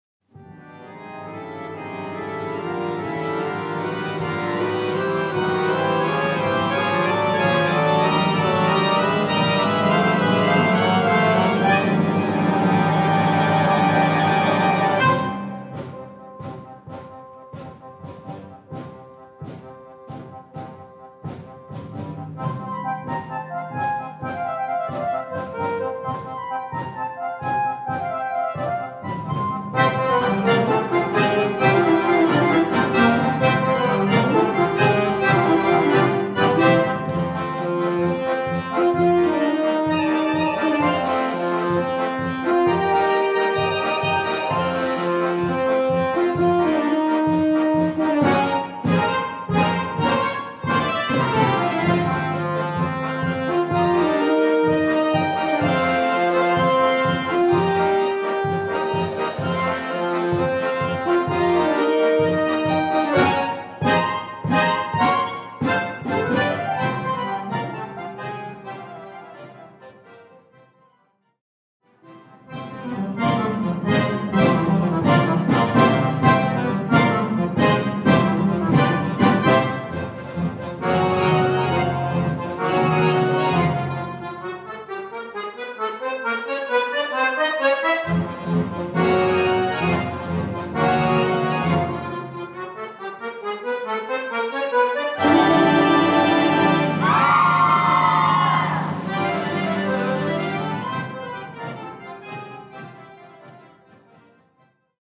Répertoire pour Accordéon - Orchestre d'Accordéons